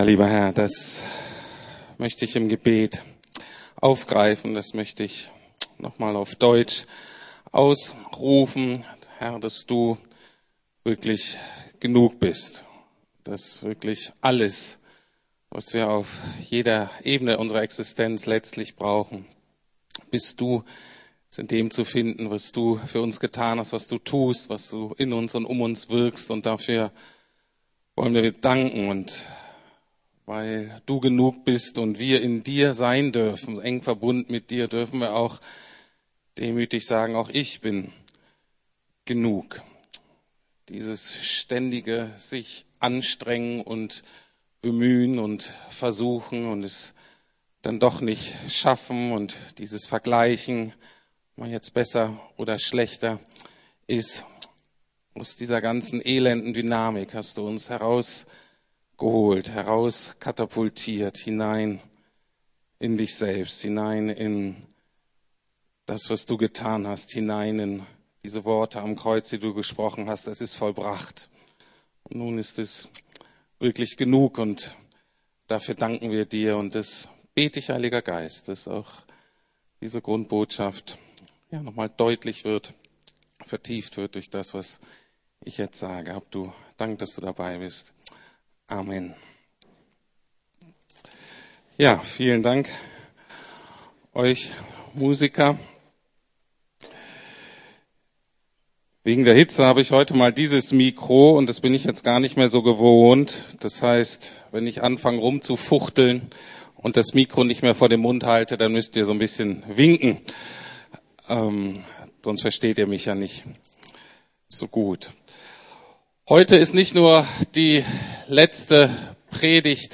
Paulus - Erfüllt mit dem Heiligen Geist ~ Predigten der LUKAS GEMEINDE Podcast